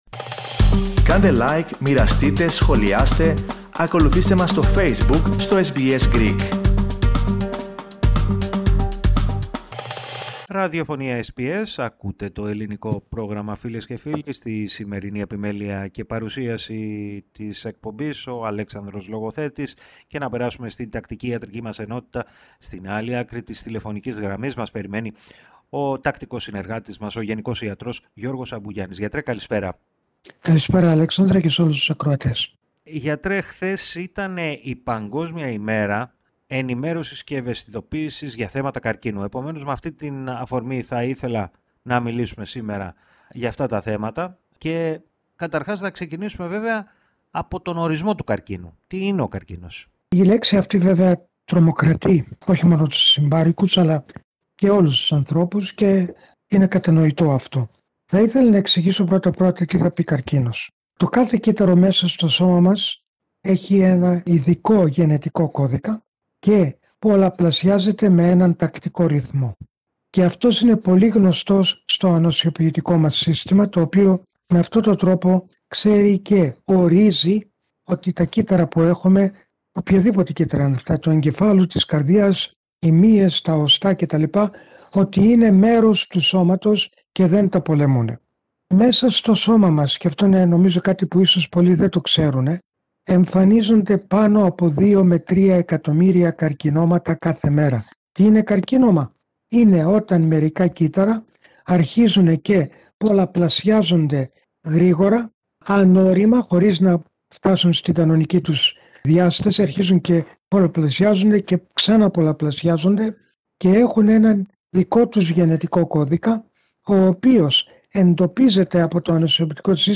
Ακούστε ολόκληρη τη συνέντευξη, πατώντας το σύμβολο στο μέσο της κεντρικής φωτογραφίας.